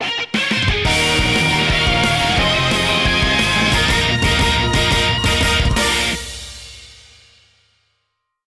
Higher quality rip from the Wii U version.